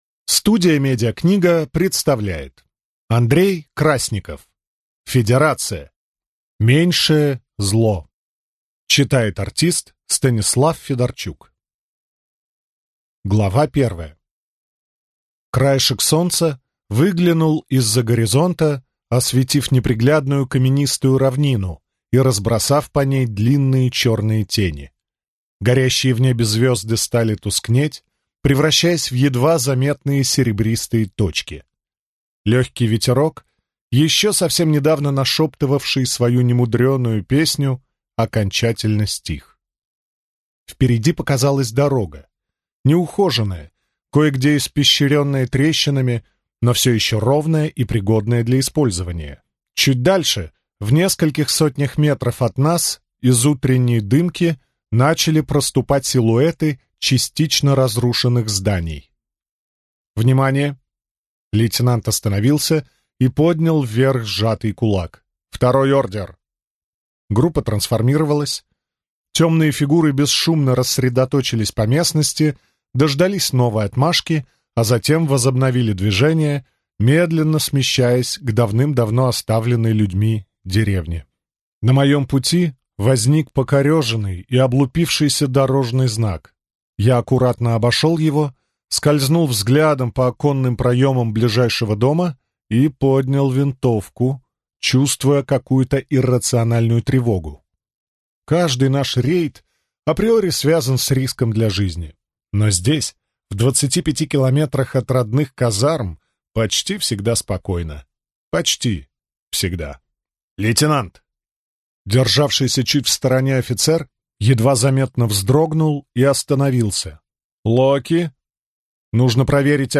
Аудиокнига Меньшее зло | Библиотека аудиокниг